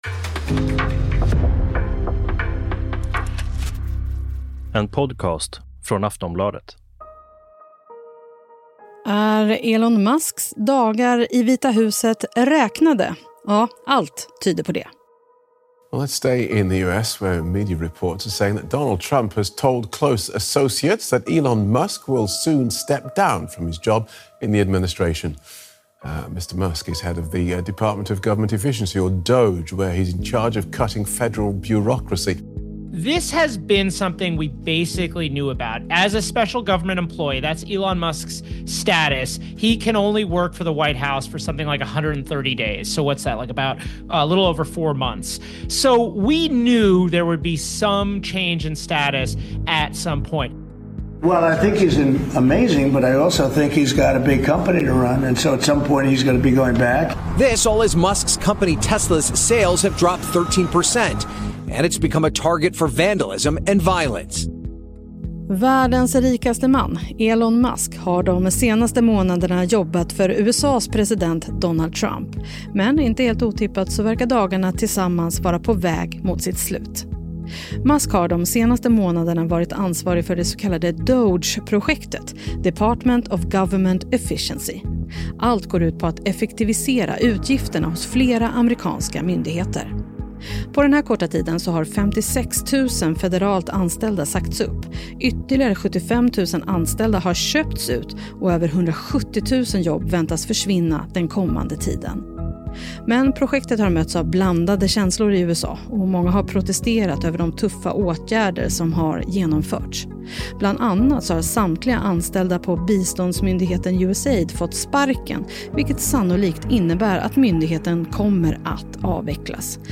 Klipp från: DW News, Bloomberg podcast, NBC News.